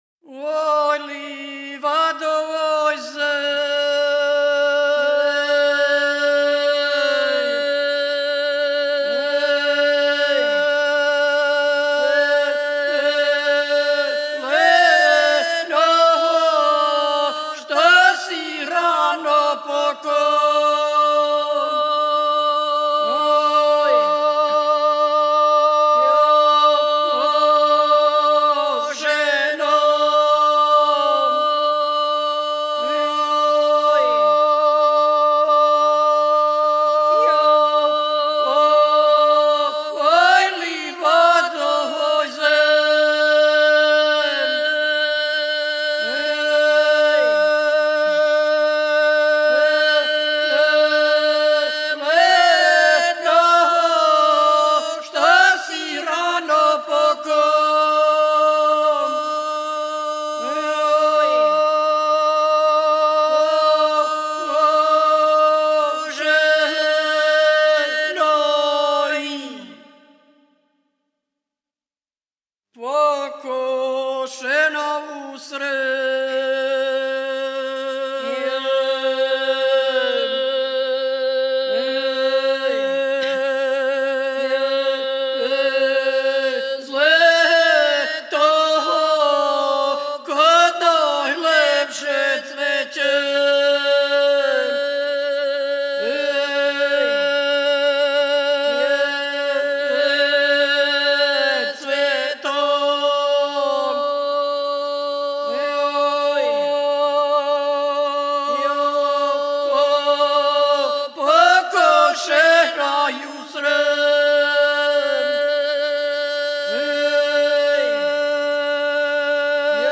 CLAMOUR SINGING
This very peculiar type of song comes from Western Serbia. In the past, it could be heard clamouring from afar over the mountains and foothills in the region of Zlatar, Tara and Zlatibor.
Clamour singing requires powerful and voluminous voice, healthy heart and strong lungs, as the  singers need to hold breath for prolonged periods of time. The songs are performed in duets – male, female or mixed – with one singer starting the song and the other joining in, taking care to match the voice and timbre of the first singer. They keep the flow going by taking turns to fill the lungs so the flow never stops.
Simple melodies intertwine, come together or move apart and in the process frequently produce the intervals of a second.